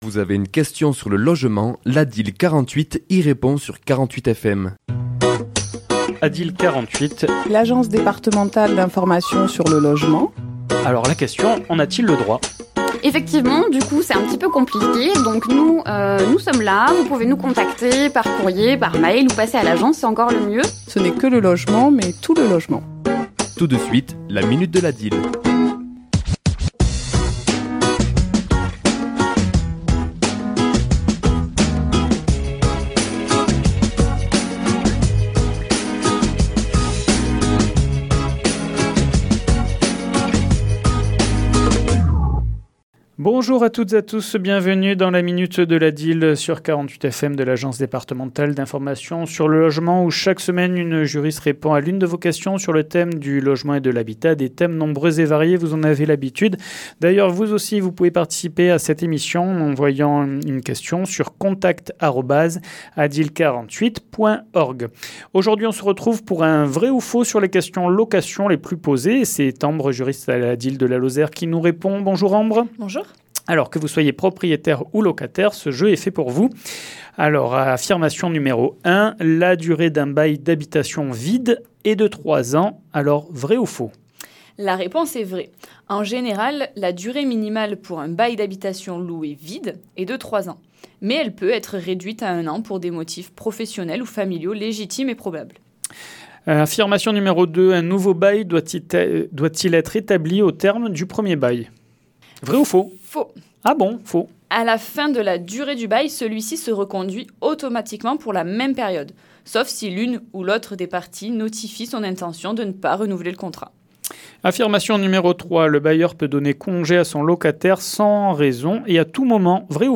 Chronique diffusée le mardi 21 Octobre à 11h et 17h10